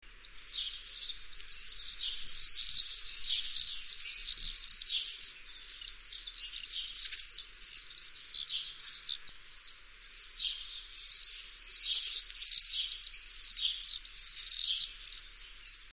Veu : Llarg i estrident xisclet i un ràpid xerric ( so )
falciot.mp3